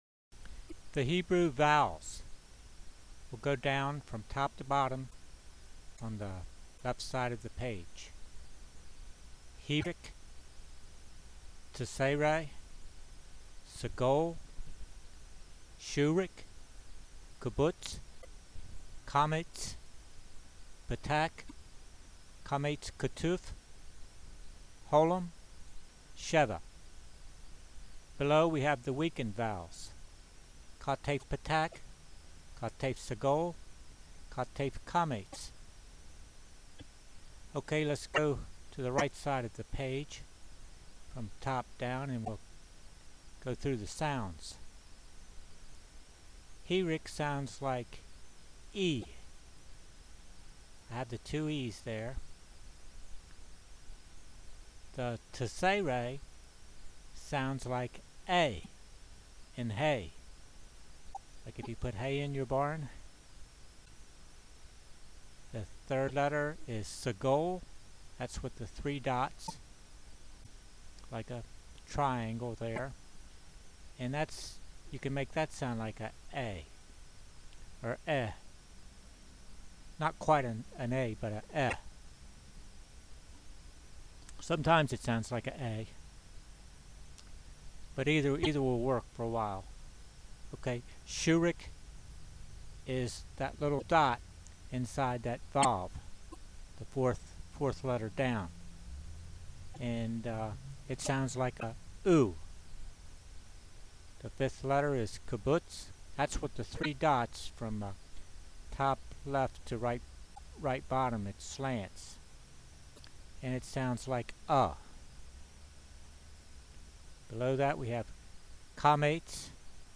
§ 7 It seems to me Segol has an “e” sound as in “met”, unless it is accented or followed with a yud, then is more like an “a” sound in “hay”.
vowels_voice.mp3